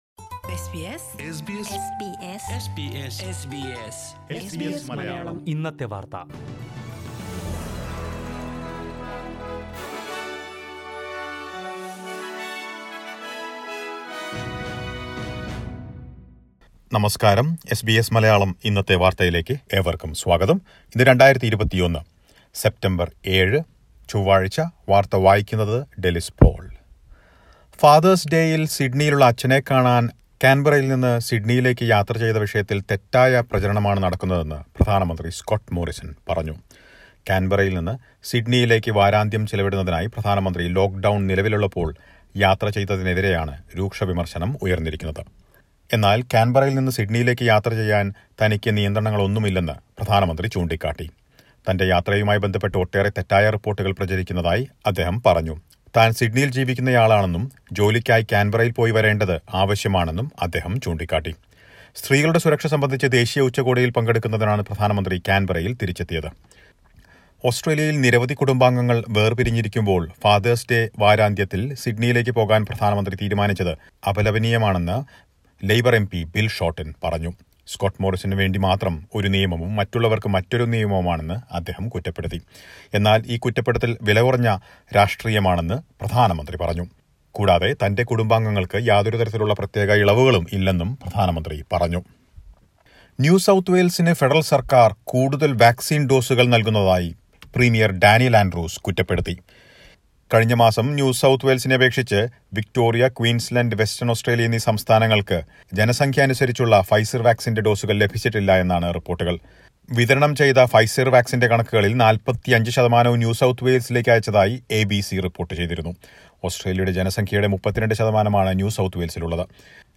709_bulletin.mp3